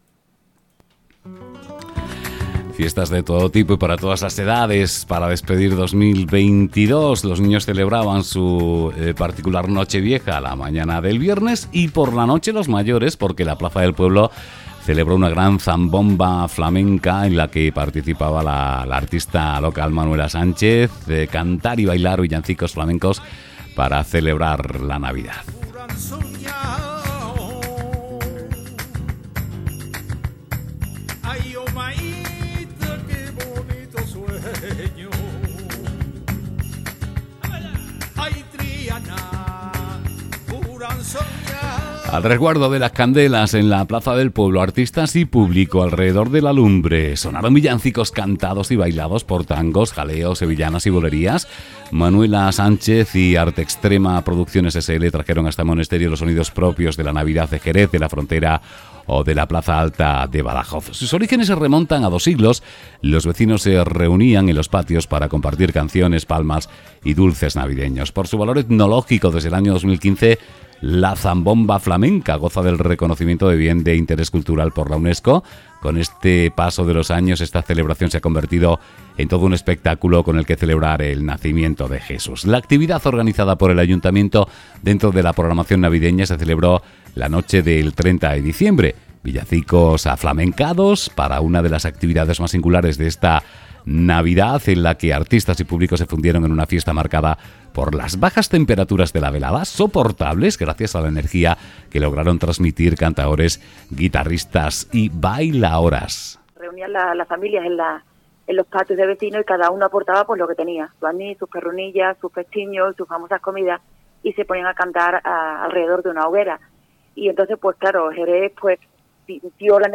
La Plaza del Pueblo de Monesterio celebra una gran Zambomba Flamenca
4uIT6ZAMBOMBAFLAMENCA.mp3